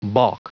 Prononciation du mot balk en anglais (fichier audio)
Prononciation du mot : balk